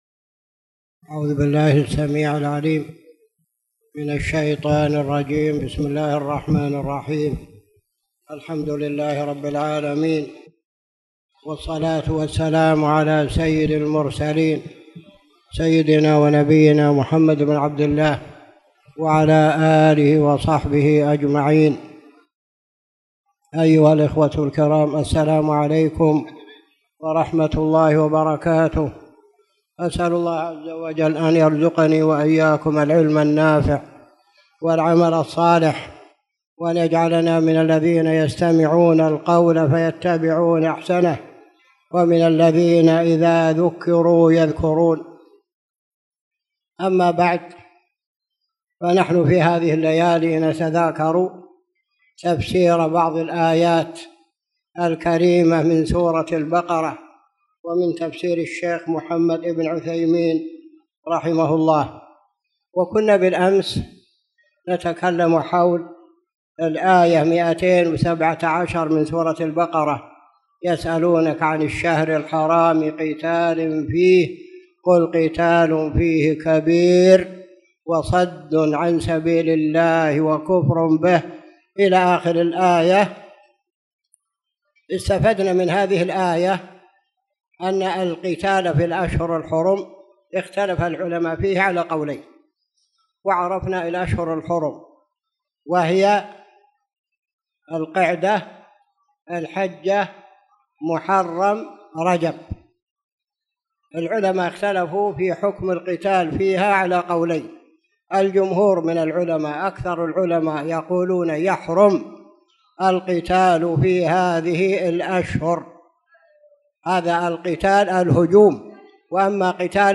تاريخ النشر ١٠ رمضان ١٤٣٧ هـ المكان: المسجد الحرام الشيخ